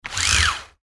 Звуки жалюзи, занавесок
Шум опускания римских штор